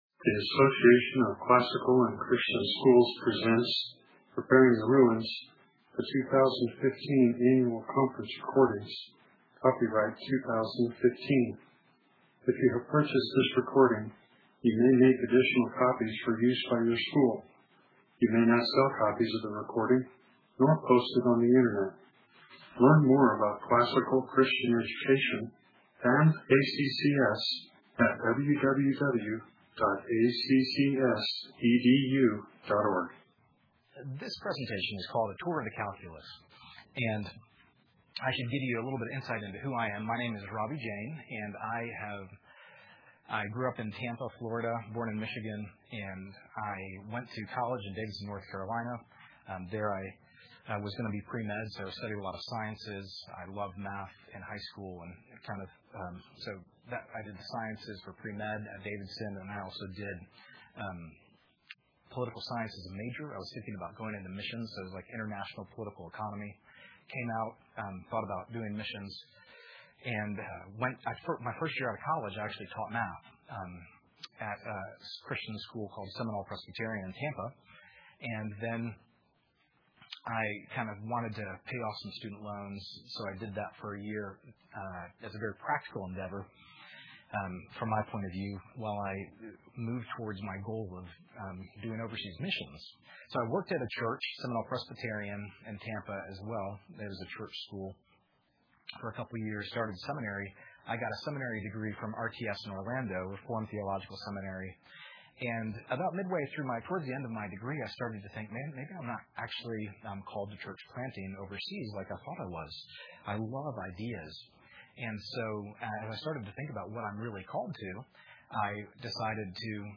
2016 Practicum Talk, 0:59:04, 7-12, Math
Dec 19, 2018 | 7-12, Conference Talks, Library, Math, Practicum Talk | 0 comments